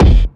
GS Phat Kicks 007.wav